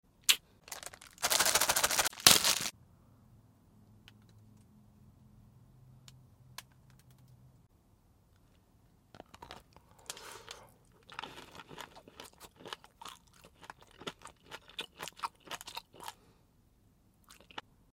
ASMR | Giant Milk Chocolate sound effects free download